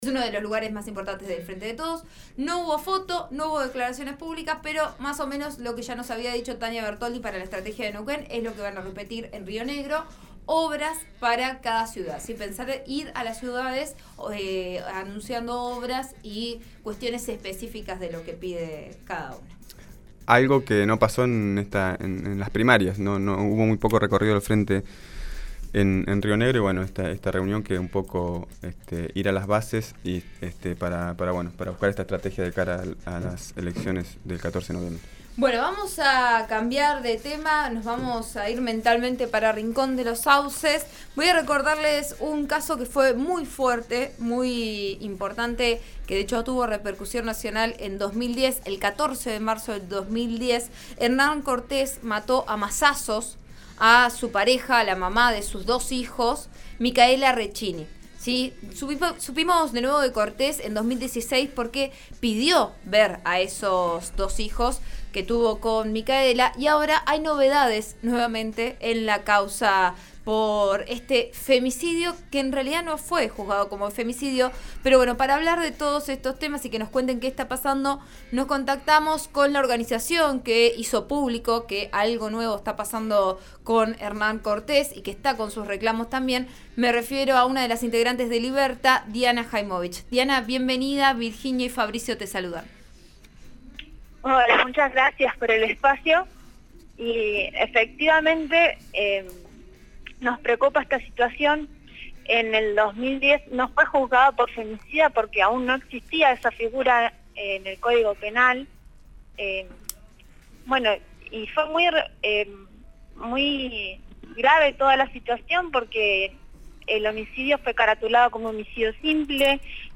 explicó los detalles en «Vos A Diario» (RN RADIO).